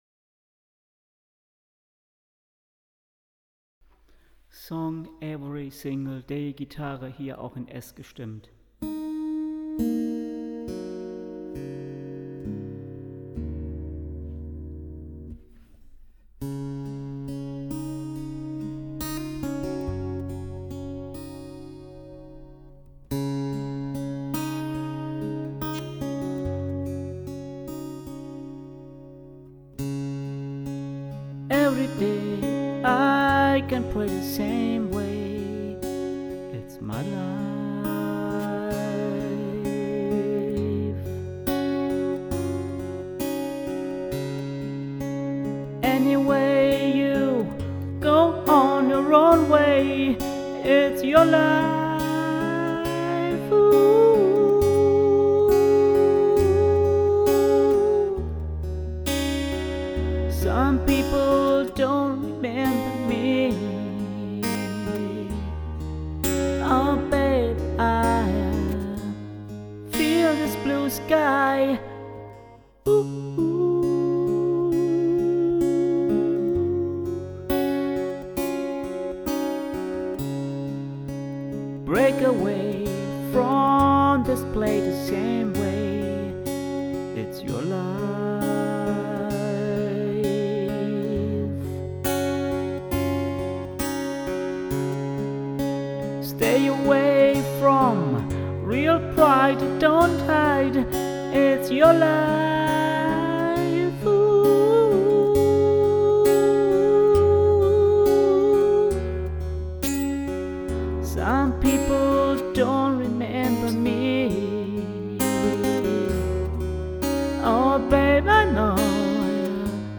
Well one can say aside the mainstream folk music.